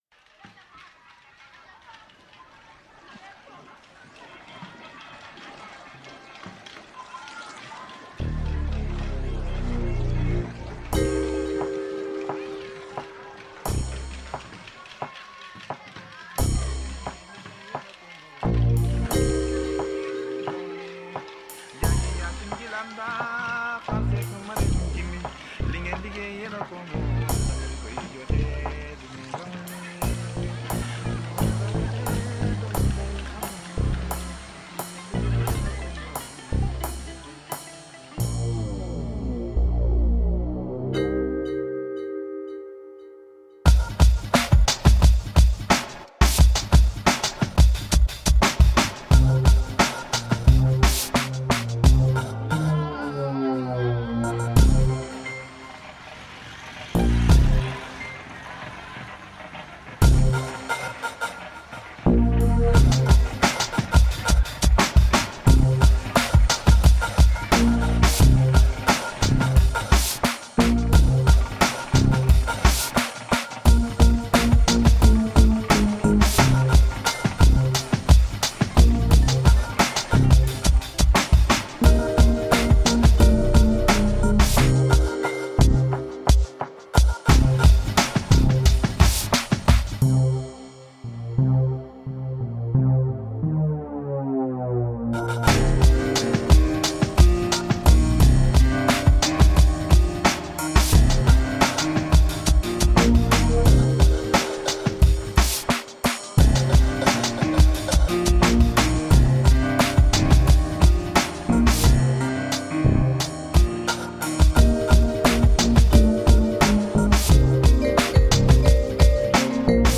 Groove soaked ambient chill.